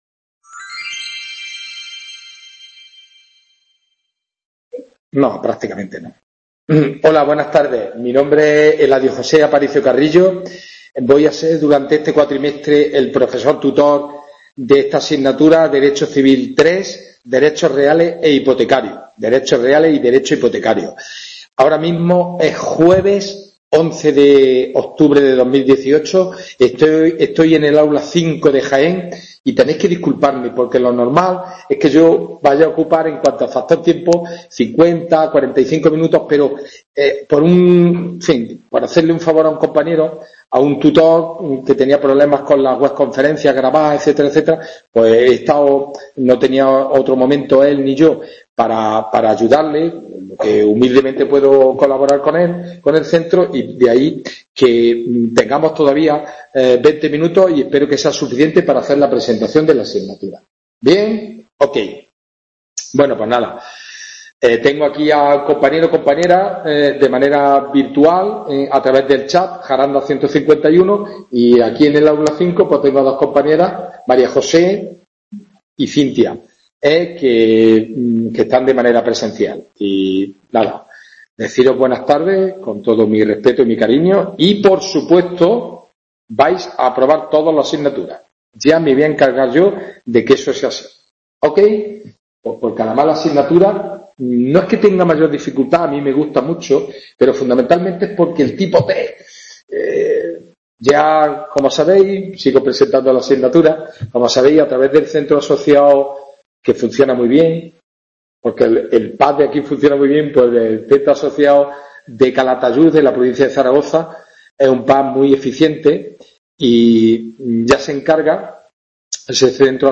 WEB CONFERENCIA